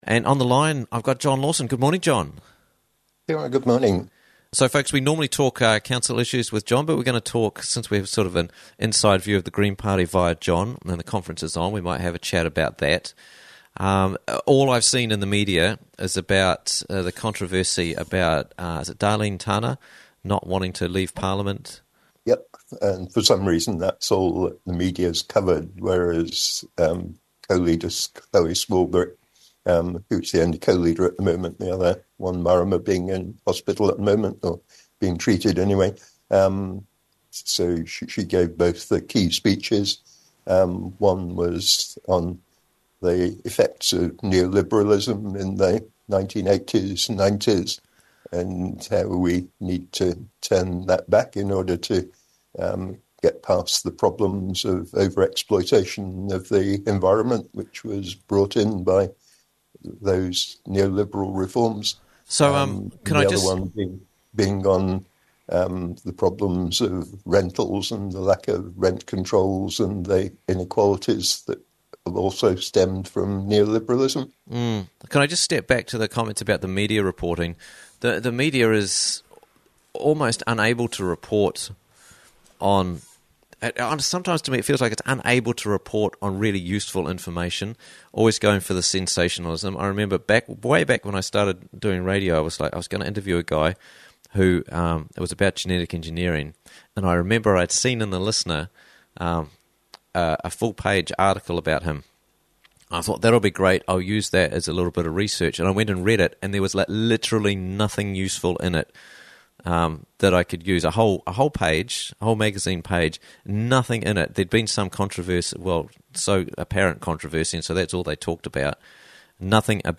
What's Really Happening in the Green Party - Interviews from the Raglan Morning Show